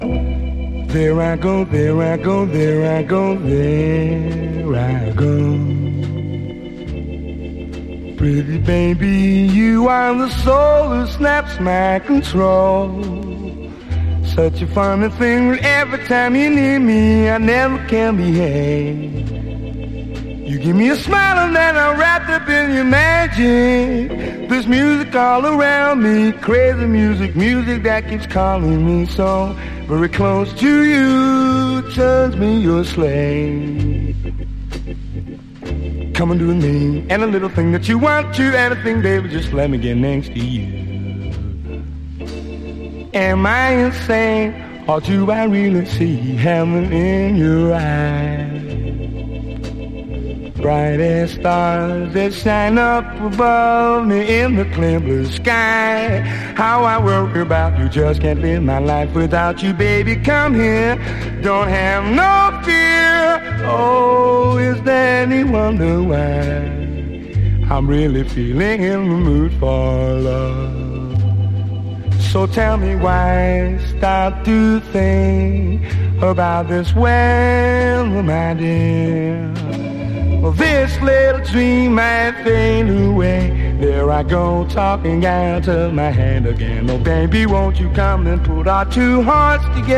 モッド・クラシックなキラー・カヴァー多数の1964年録音！